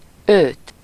Ääntäminen
Synonyymit clé des champs Ääntäminen France (Région parisienne): IPA: [lə] Tuntematon aksentti: IPA: /lɵ̞/ IPA: /lø/ Haettu sana löytyi näillä lähdekielillä: ranska Käännös Ääninäyte 1. őt 2. az Suku: m .